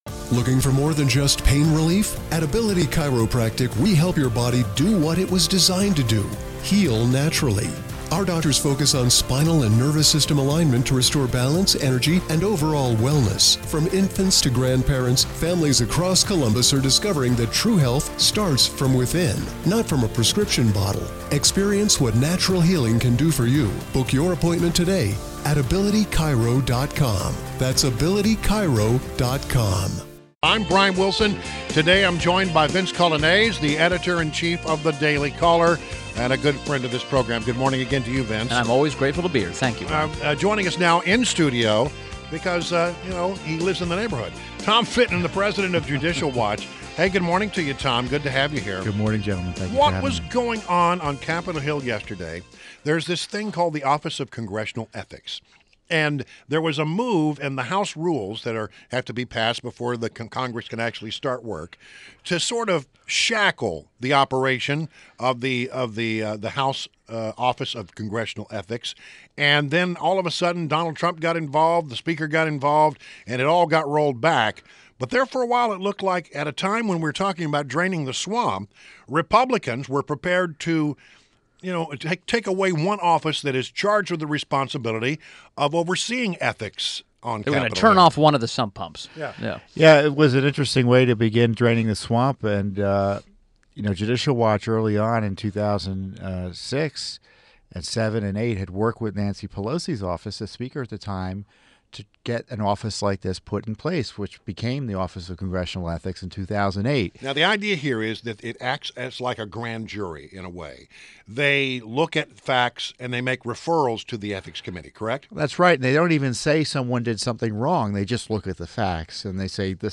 WMAL Interview - TOM FITTON - 01.04.17
INTERVIEW – TOM FITTON – President of Judicial Watch